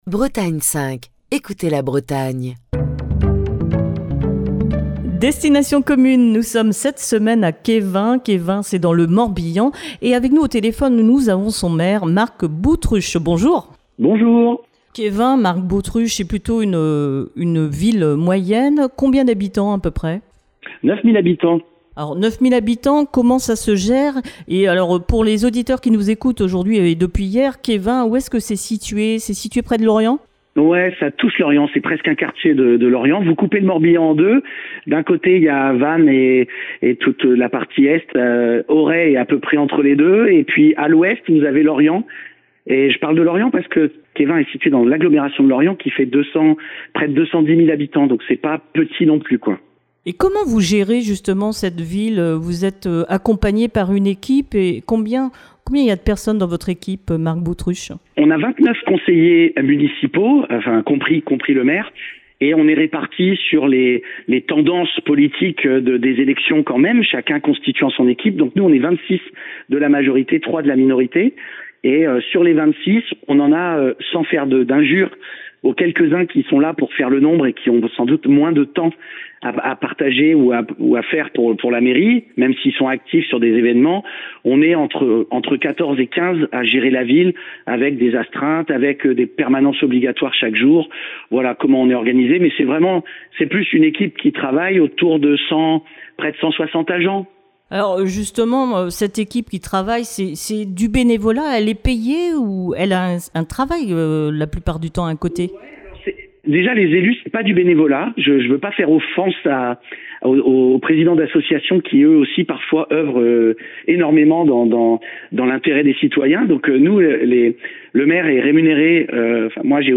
Cette semaine, Destination commune fait escale à Quéven dans le Morbihan. Marc Boutruche, le maire de Quéven présente sa commune